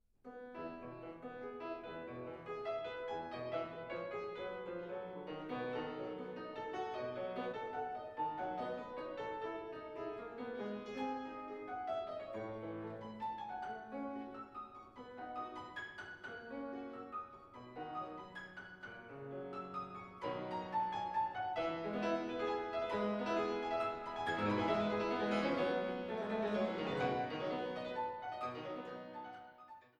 Fortepiano und Clavichord
Grande Sonate pour le Pianoforte f-Moll